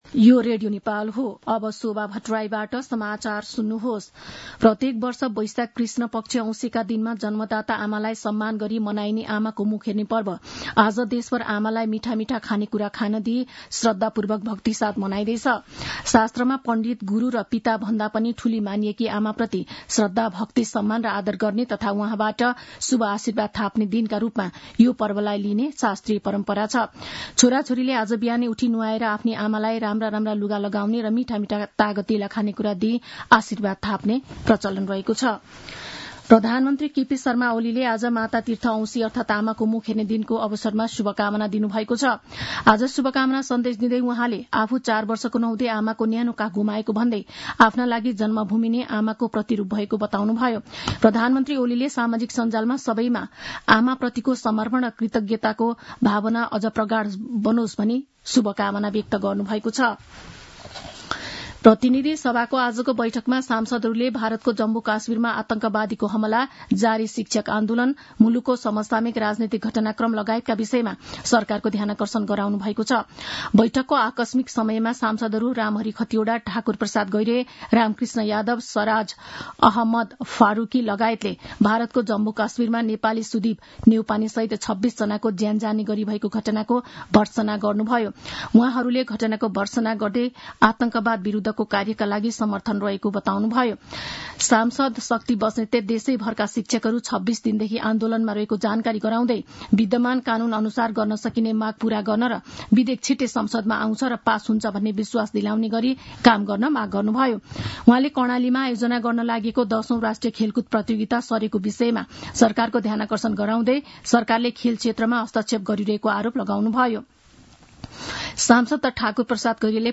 मध्यान्ह १२ बजेको नेपाली समाचार : १४ वैशाख , २०८२
12-am-news-1-13.mp3